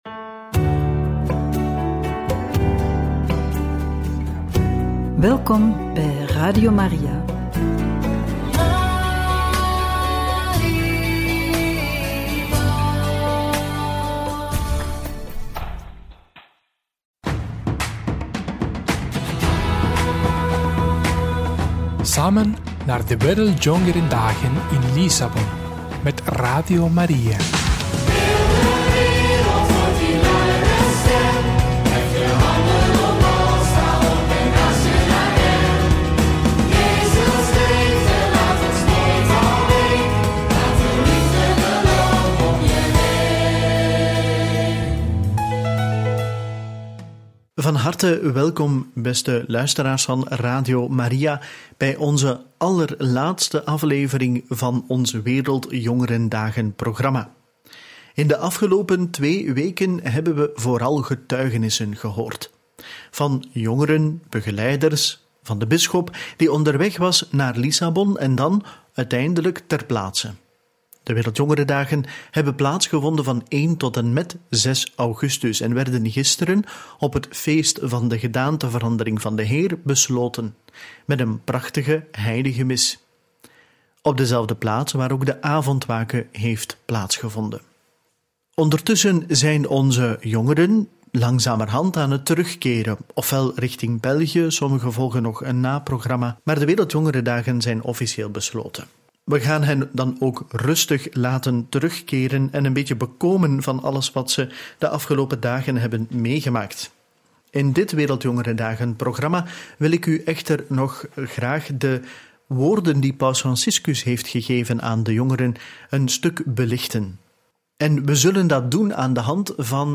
WJD 2023 – Paus Franciscus aan het woord! Verwelkoming – Kruisweg – Avondwake – Radio Maria